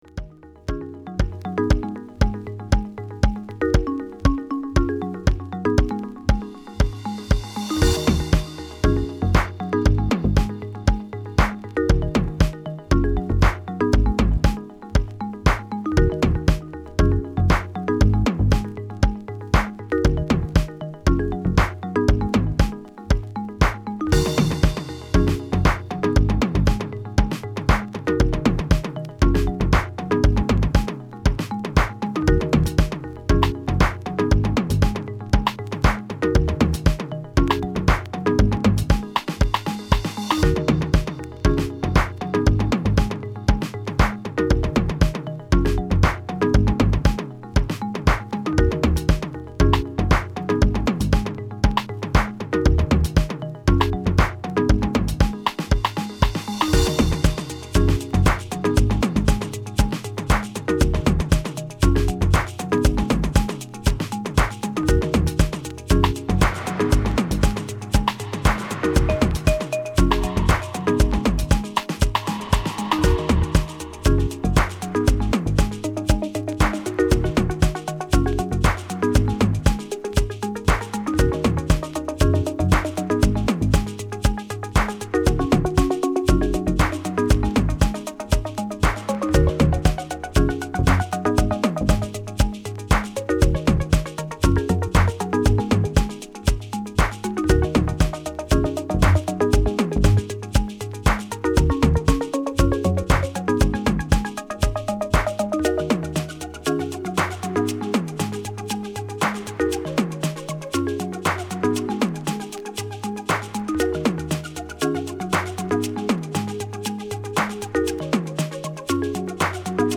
House / Techno
Keyboards